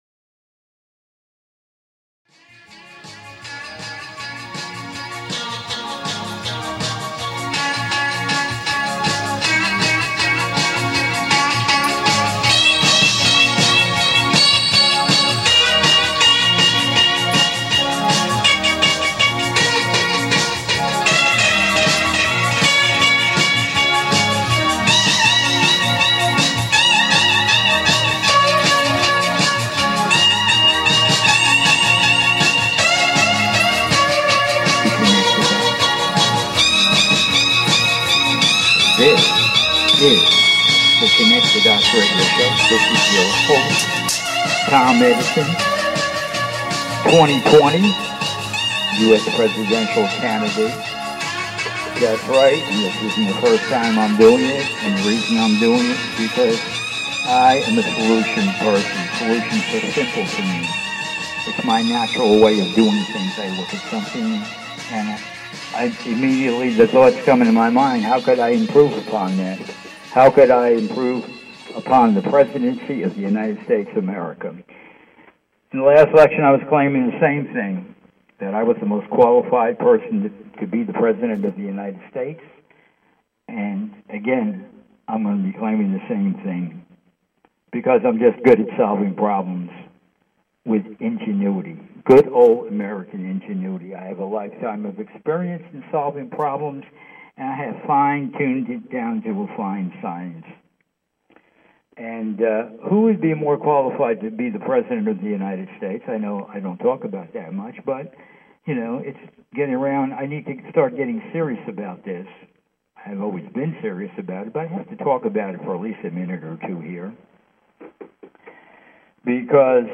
"CONNECT THE DOTS" is a call in radio talk show